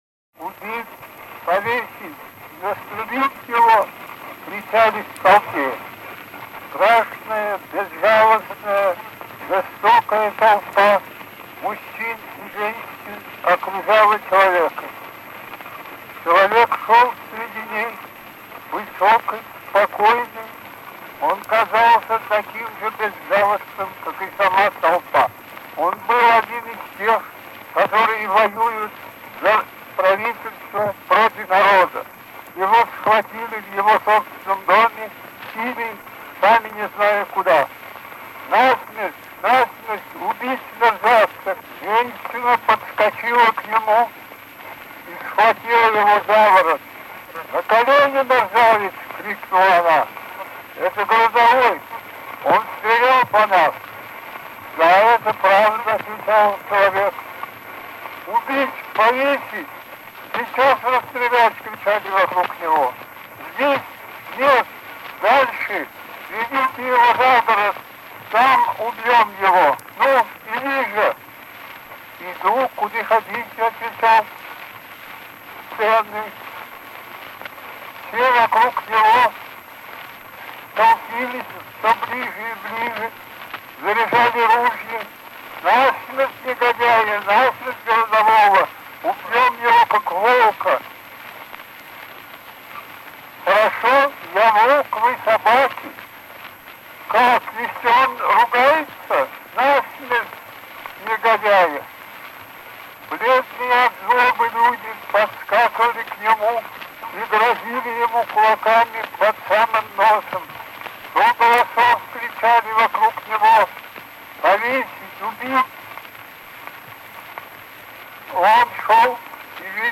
Аудиокнига Статьи и письма: фонографические и граммофонные записи 1908-1909 гг | Библиотека аудиокниг